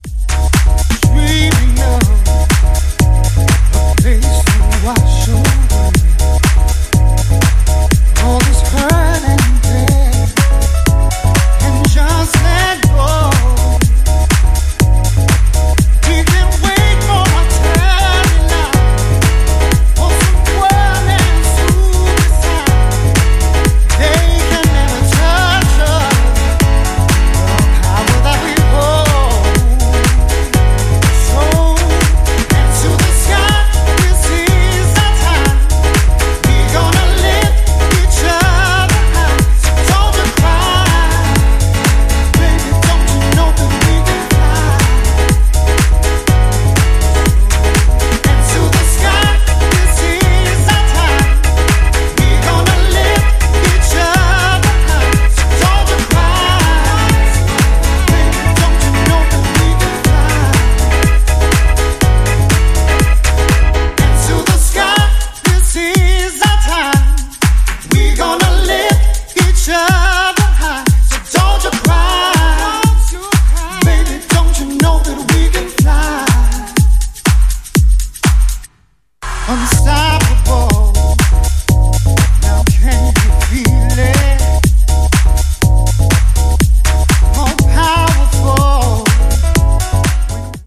ジャンル(スタイル) SOULFUL HOUSE / NU DISCO